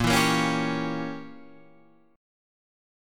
A#dim7 chord